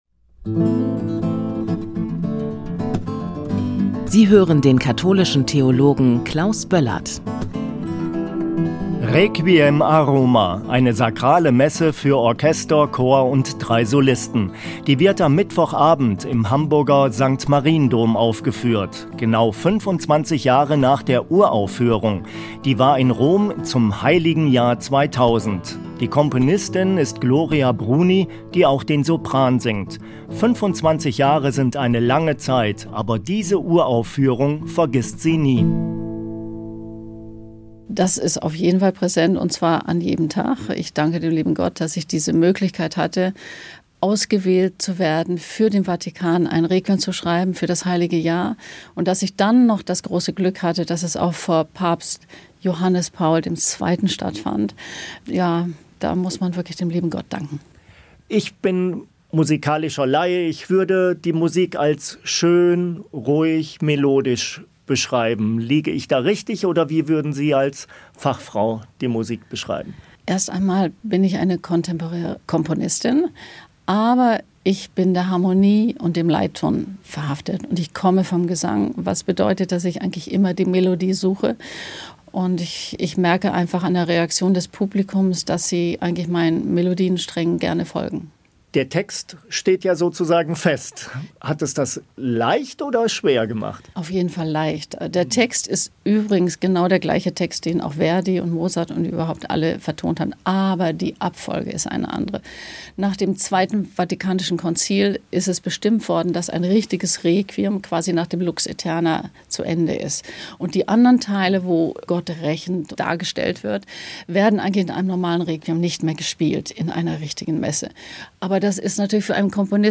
im Gespräch mit der Komponistin